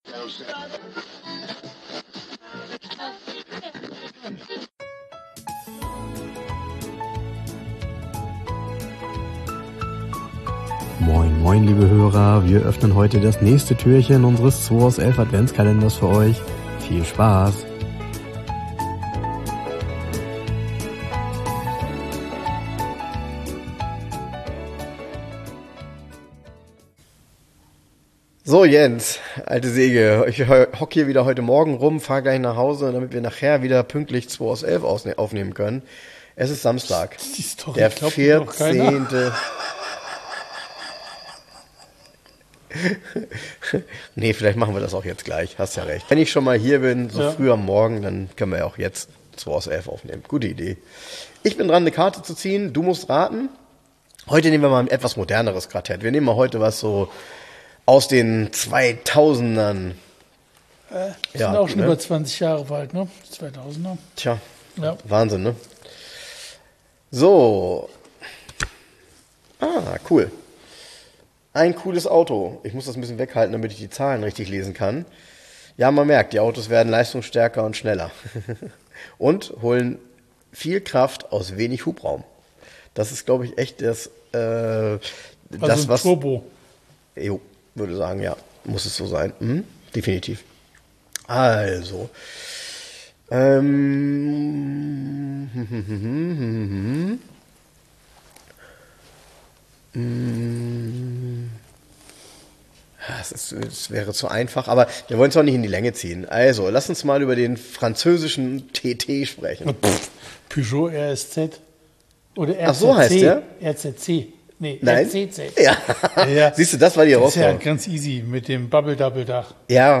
aufgenommen in der Garage 11 in Hamburg. Wir reden über Neuigkeiten aus der Szene und dem Klassiker Markt und interviewen regelmäßig Gäste und therapieren deren automobile Vergangenheit.